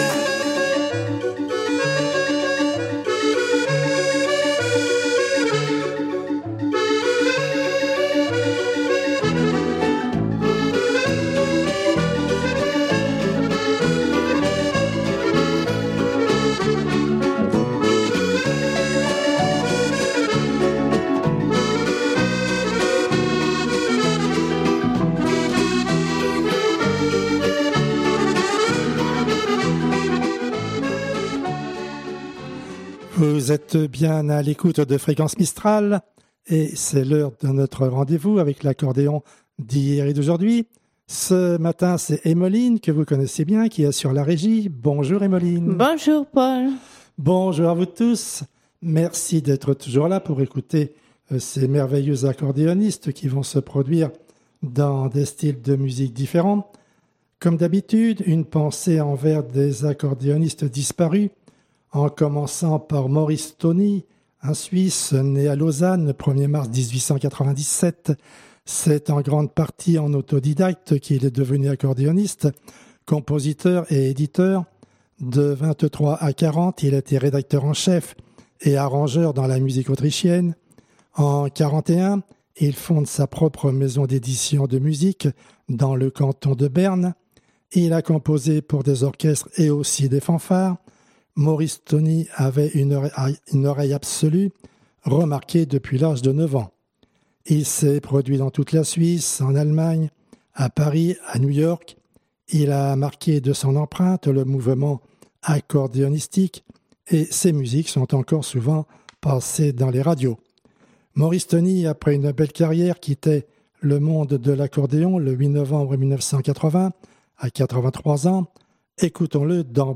Accordéon d'hier et d'aujourdhui du 11 Juin 2016